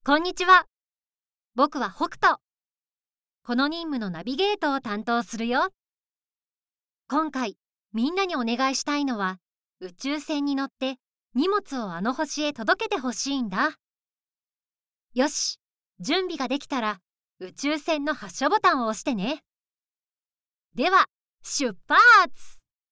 優しく、誠実な声が特徴です。
男の子のキャラクター
female05_48.mp3